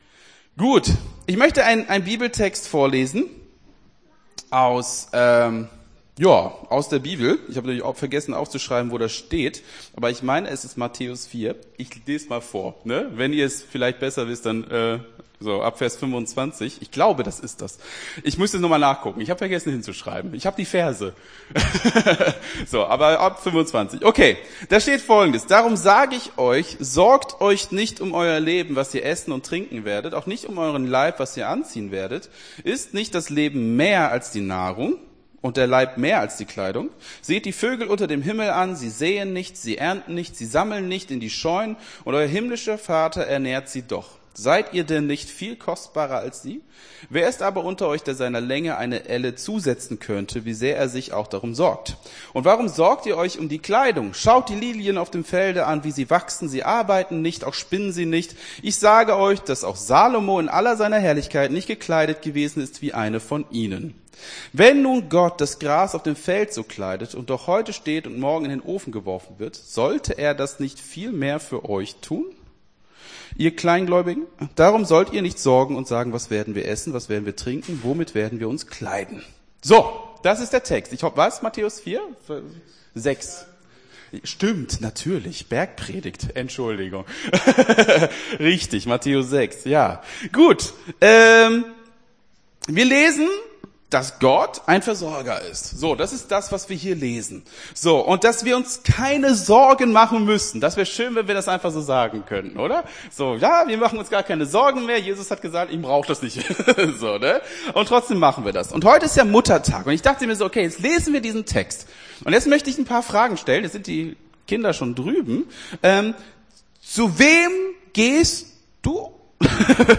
Gottesdienst 14.05.23 - FCG Hagen